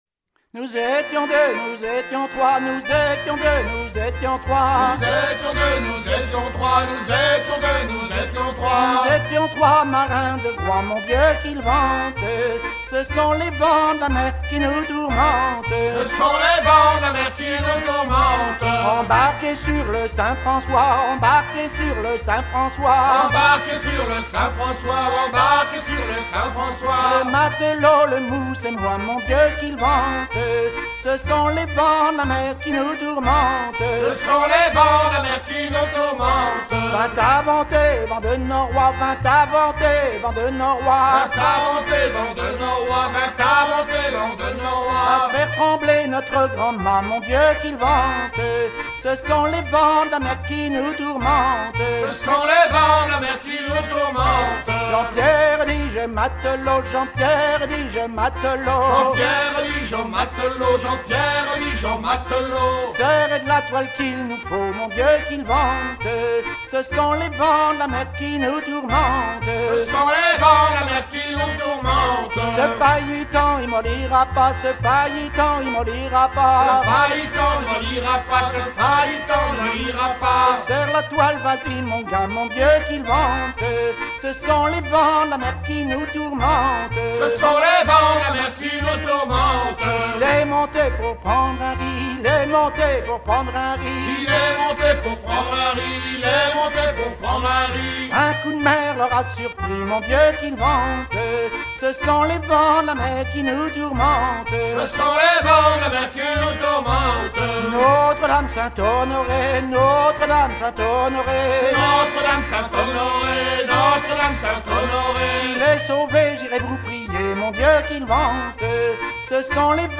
vielleux
Genre laisse
Pièce musicale éditée